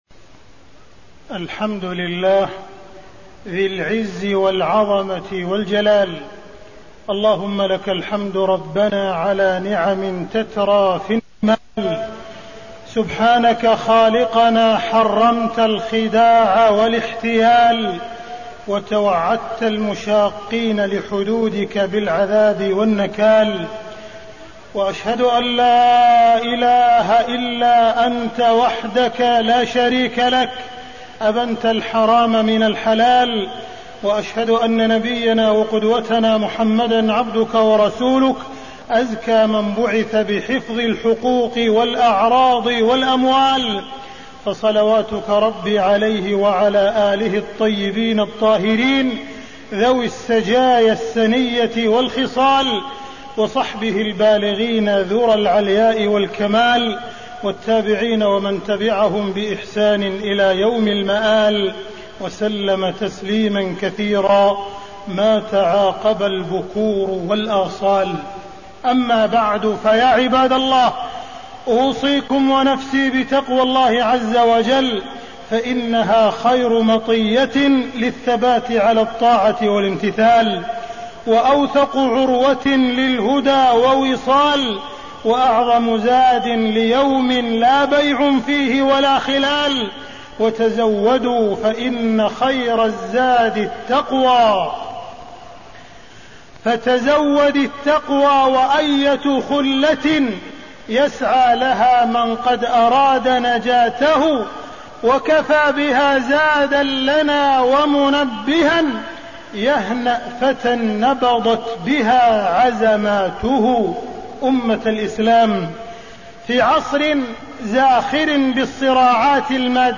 تاريخ النشر ٧ جمادى الآخرة ١٤٣١ هـ المكان: المسجد الحرام الشيخ: معالي الشيخ أ.د. عبدالرحمن بن عبدالعزيز السديس معالي الشيخ أ.د. عبدالرحمن بن عبدالعزيز السديس خطورة الإحتيال